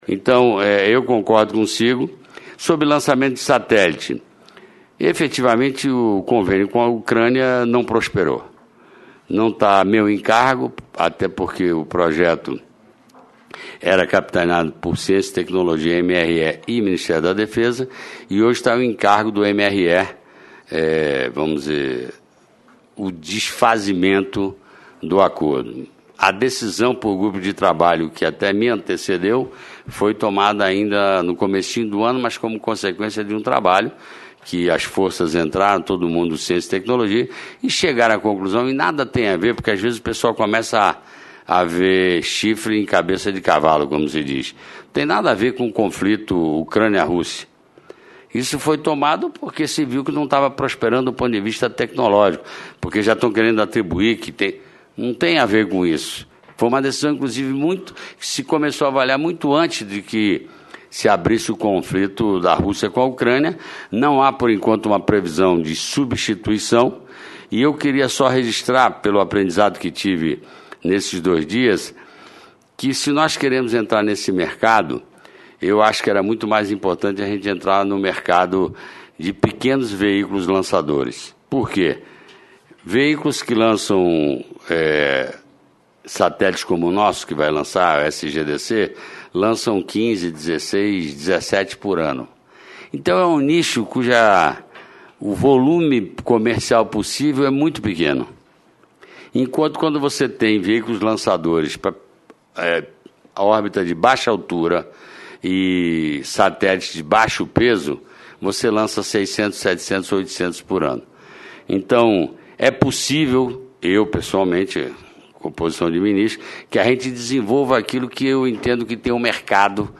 Confira a íntegra dos principais debates da Comissão de Relações Exteriores e Defesa Nacional do Senado